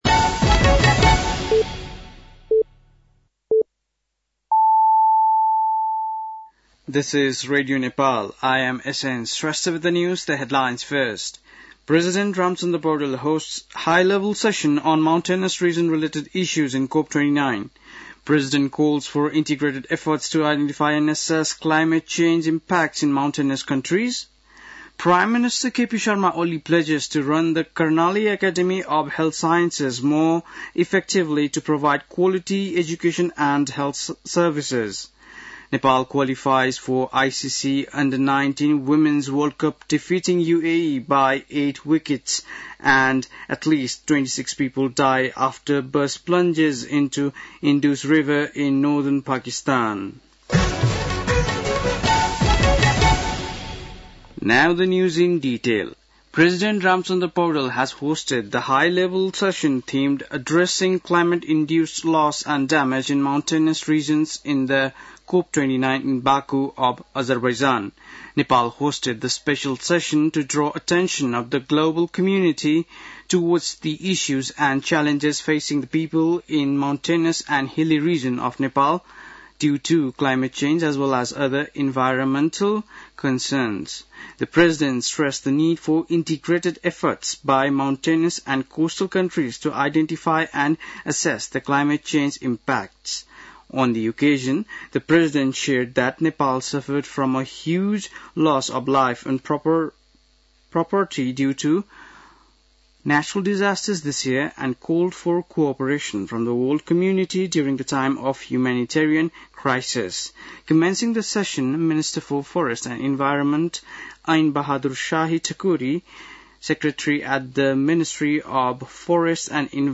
बेलुकी ८ बजेको अङ्ग्रेजी समाचार : २९ कार्तिक , २०८१
8-Pm-english-news-7-28.mp3